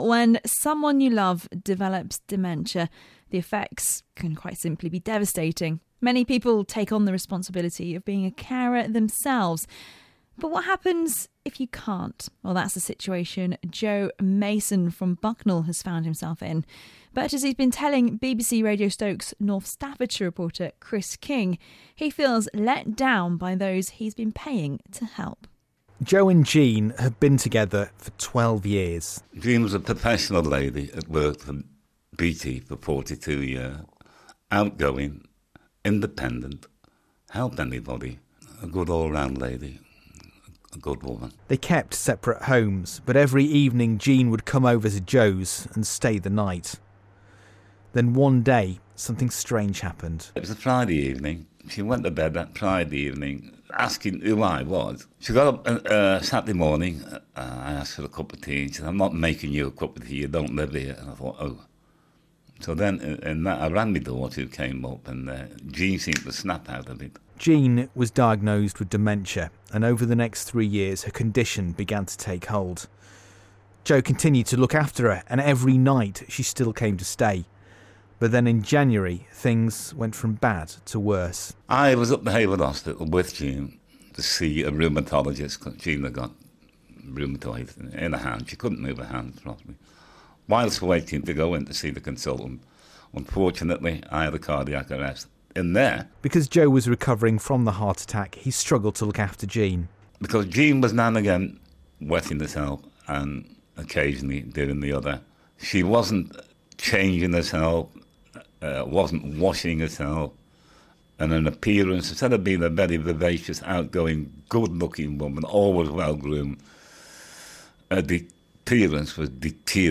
The partner of a pensioner from Bucknall says carers left her in soiled clothes, and only spent ten minutes in her home when she paid for 30 minutes. Here's my report.